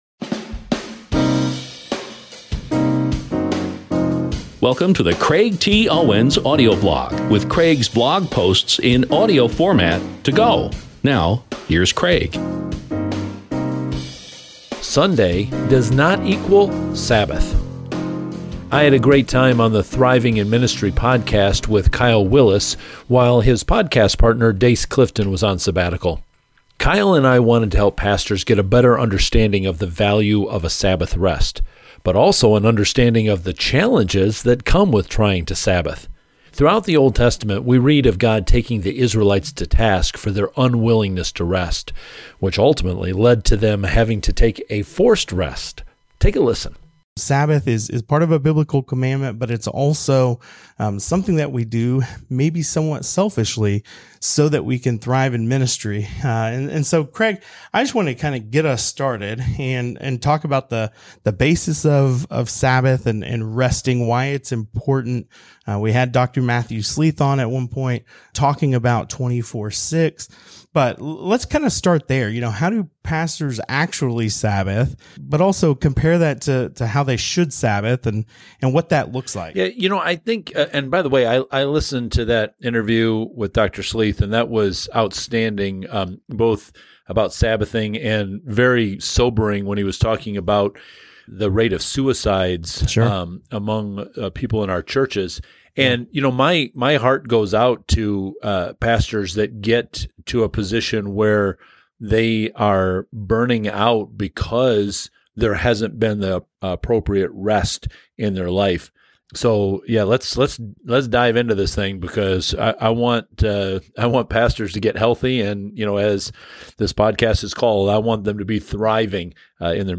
I’ll be sharing more clips from this Thriving In Ministry interview soon, so please stay tuned.